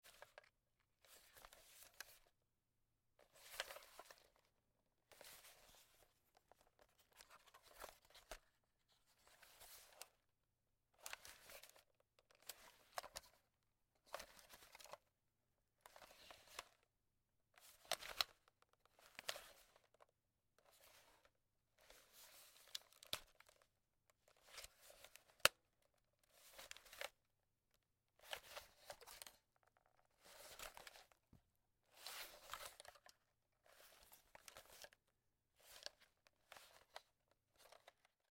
Звук работы видеокамеры в руке